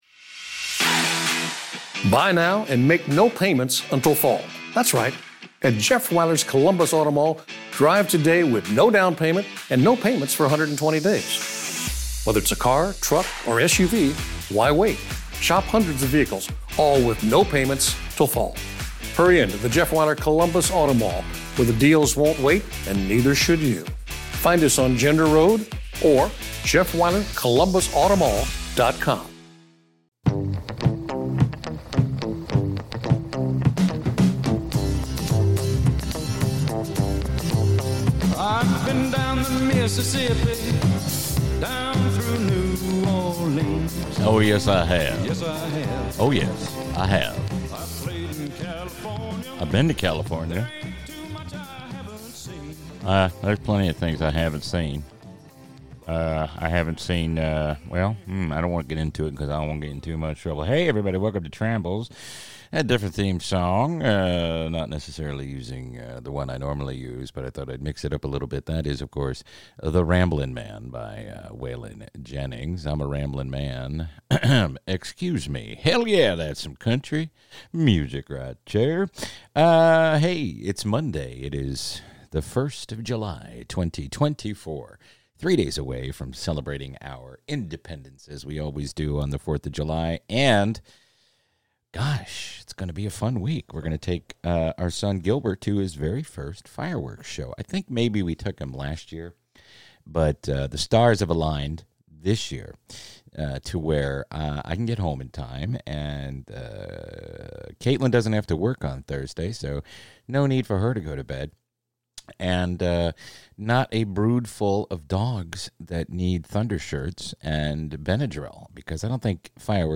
and wraps up with a short Seinfeld impression.